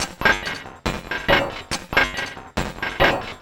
tx_perc_140_mechanism1.wav